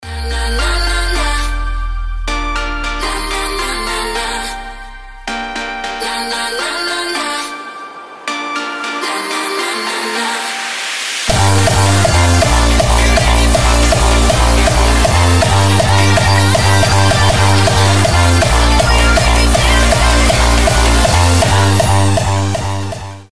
• Качество: 320, Stereo
мощные
нарастающие
быстрые
Энергичный хардстайл с интро, идеально подходящим для звонка